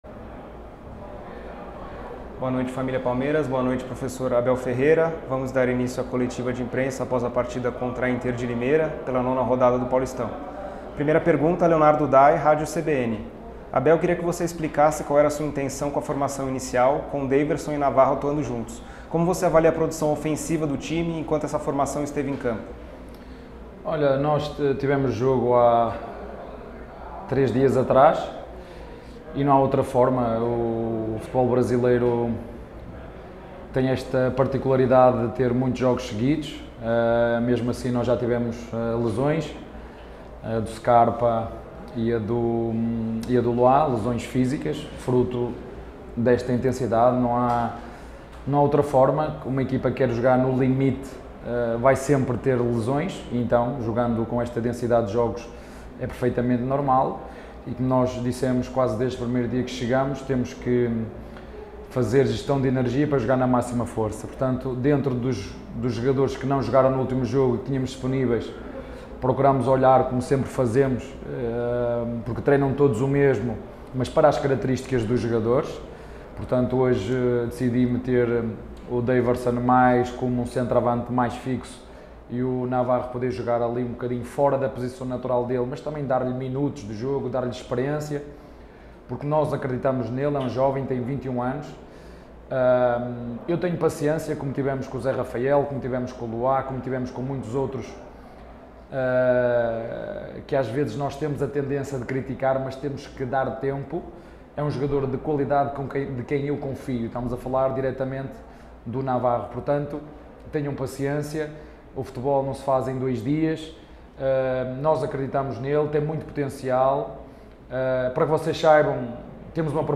COLETIVA-ABEL-FERREIRA-_-INTER-DE-LIMEIRA-X-PALMEIRAS-_-PAULISTA-2022.mp3